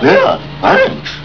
Here you will find tons of great sounds and other downloads from actual episodes of Scooby Doo. Choose from any of over 150 wav files, each of excellent sound quality.